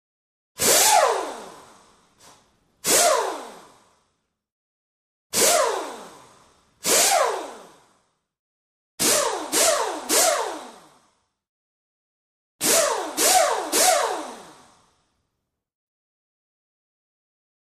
Medium Pneumatic Drill; Spurts; Numerous Drill Bursts, Varying Lengths, Air Release / Motor Spin, Medium Perspective.